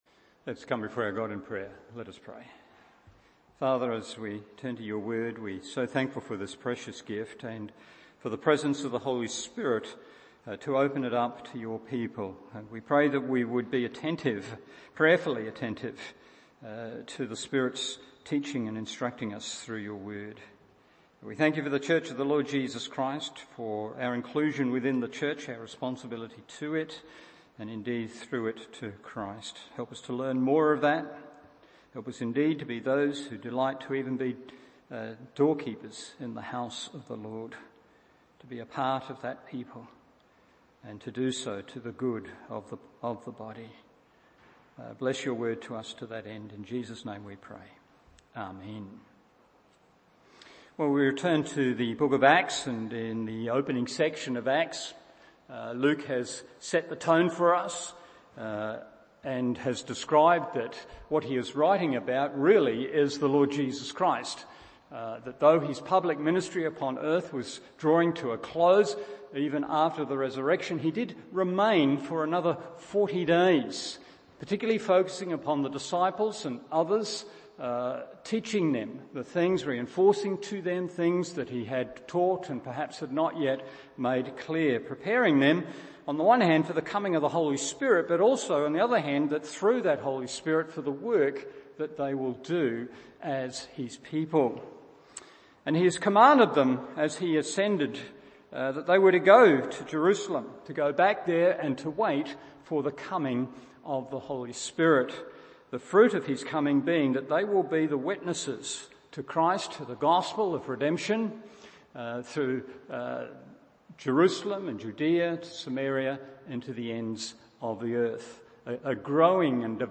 Evening Service Acts 1:12-26 1.